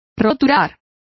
Complete with pronunciation of the translation of plowed.